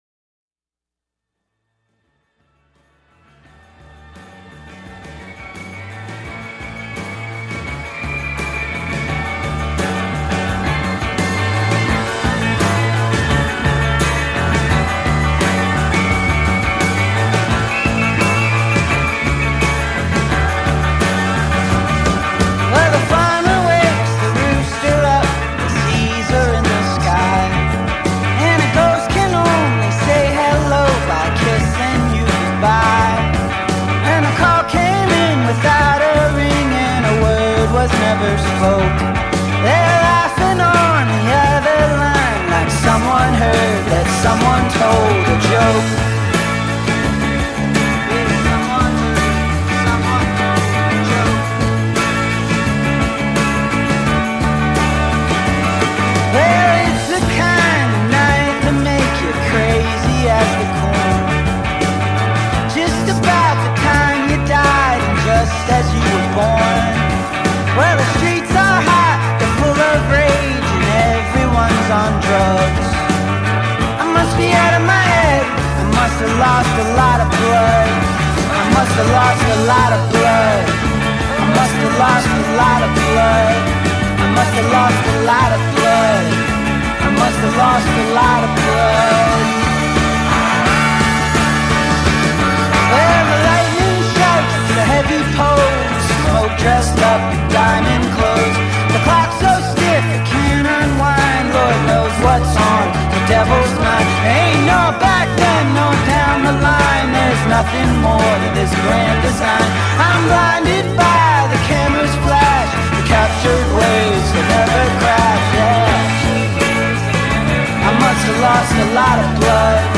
retro styled